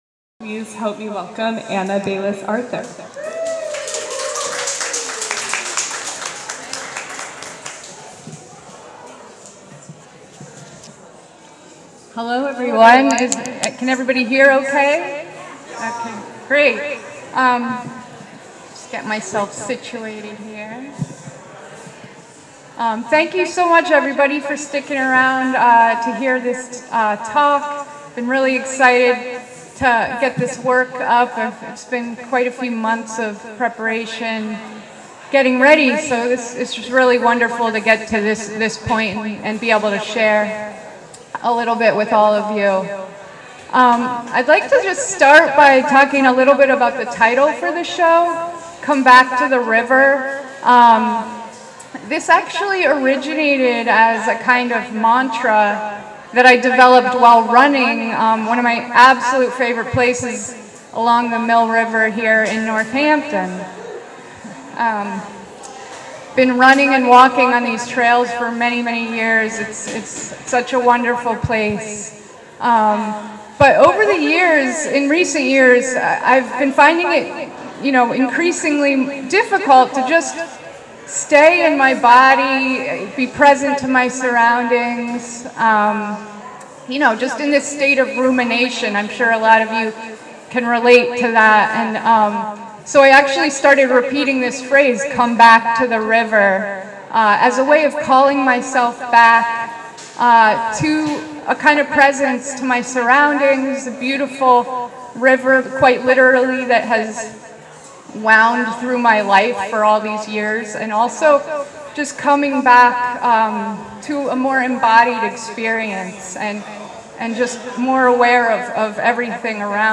Art Talk